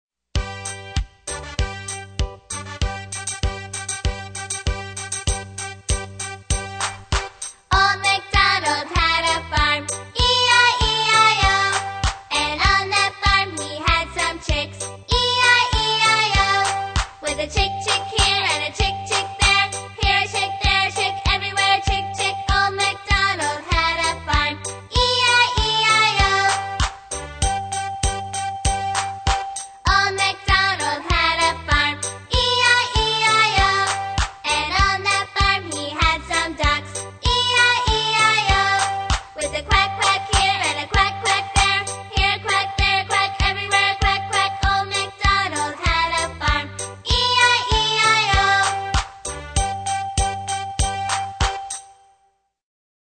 在线英语听力室英语儿歌274首 第156期:Old MacDonald had a farm(3)的听力文件下载,收录了274首发音地道纯正，音乐节奏活泼动人的英文儿歌，从小培养对英语的爱好，为以后萌娃学习更多的英语知识，打下坚实的基础。